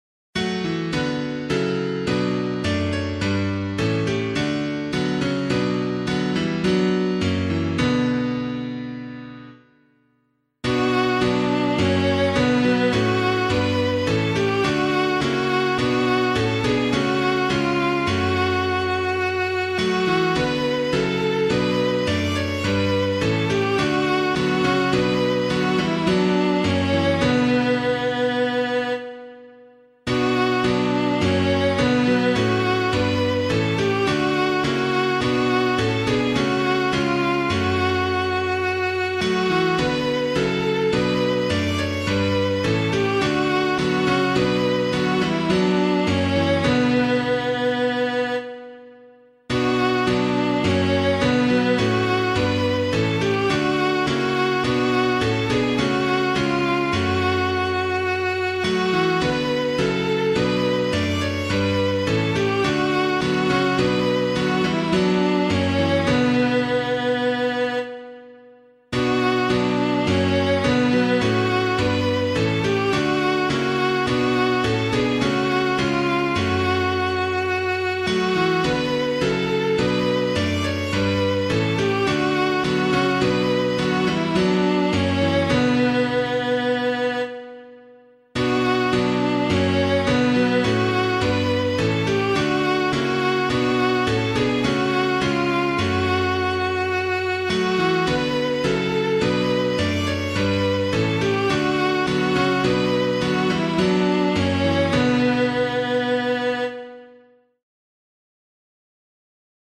piano
When All Was Formless Dark and Void [Joncas - BANGOR] - piano.mp3